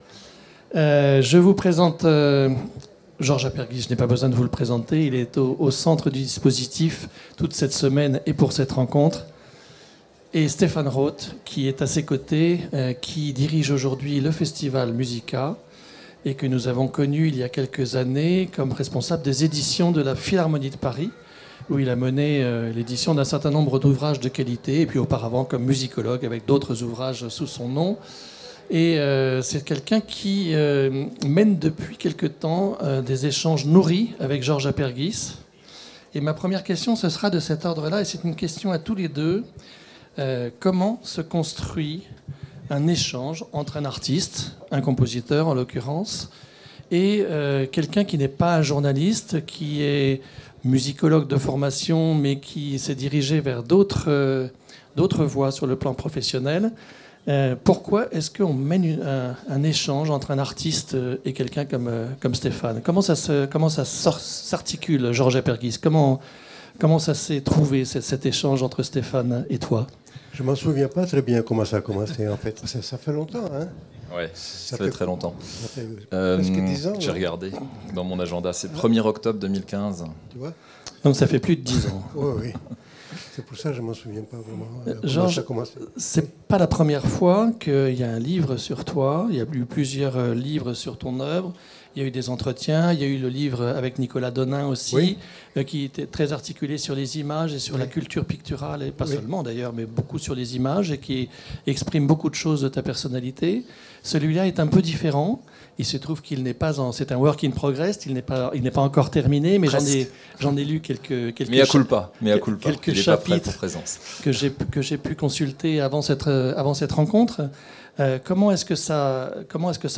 Rencontre - Georges Aperghis